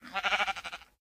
sheep_say1.ogg